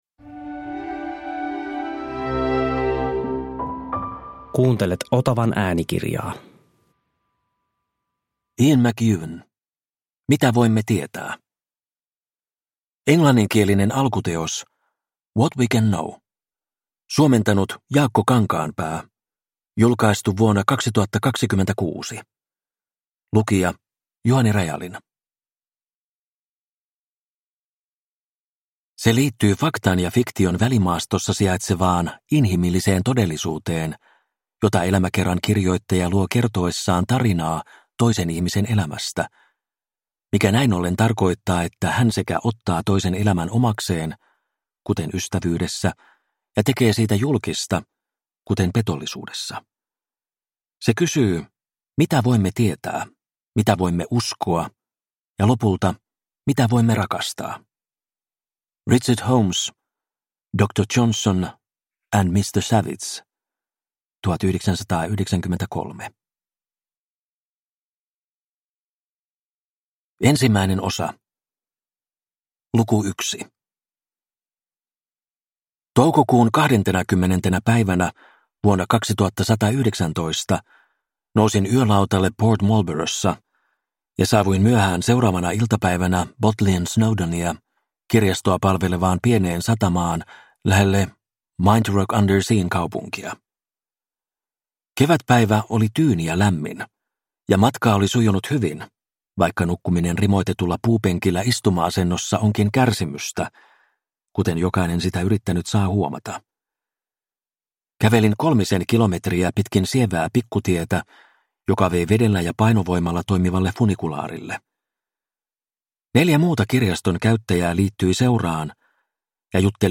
Mitä voimme tietää – Ljudbok